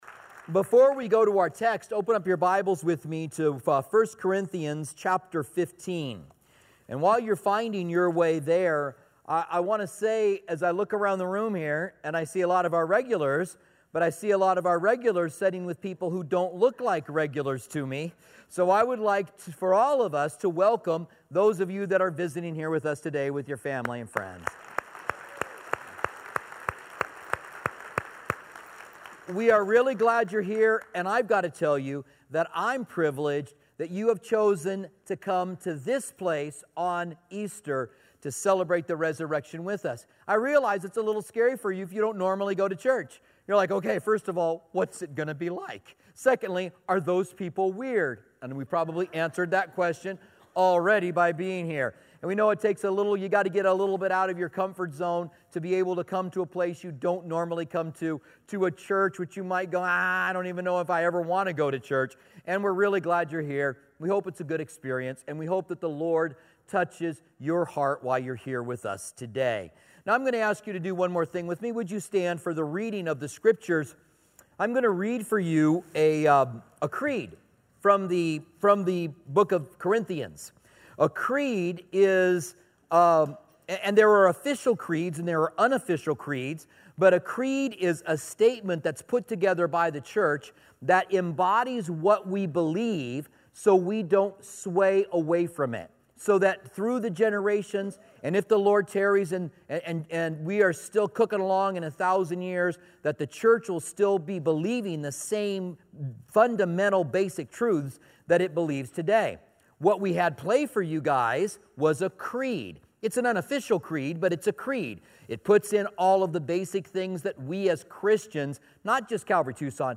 Easter Holiday Message